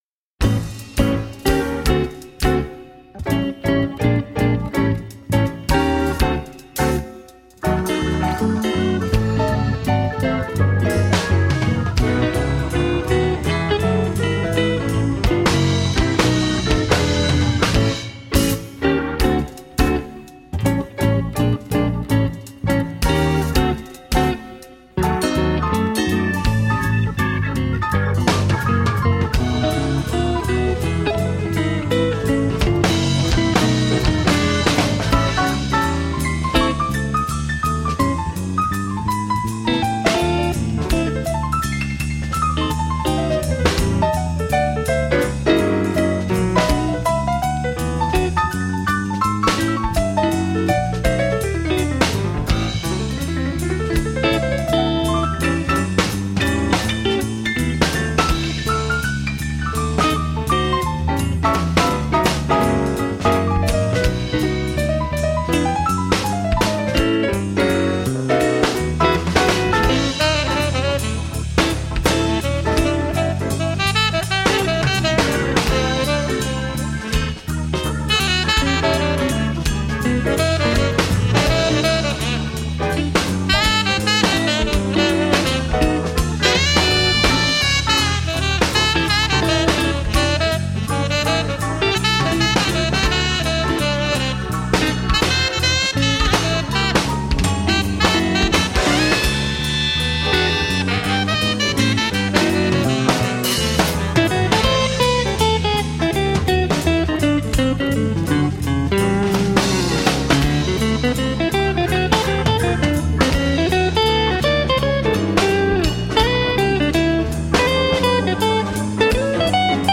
Blues guitar legend in the making.